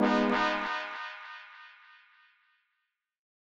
KIN Horn Fx B.wav